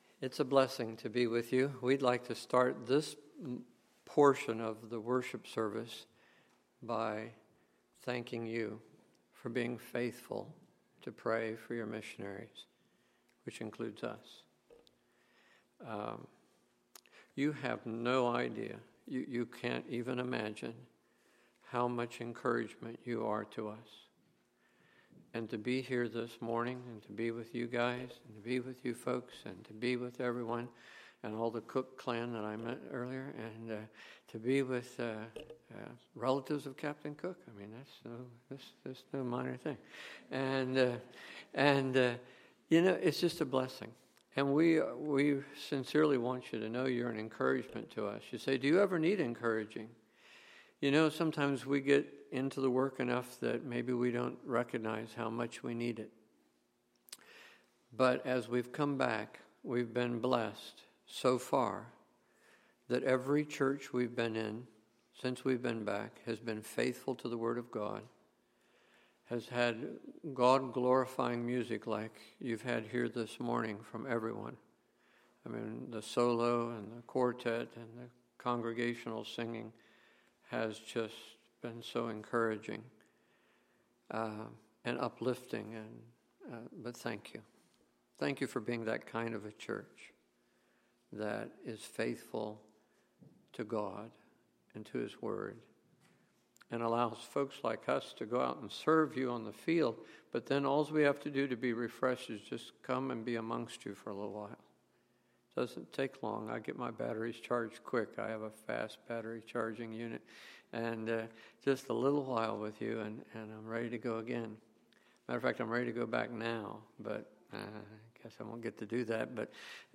Sunday, October 20, 2013 – Morning Service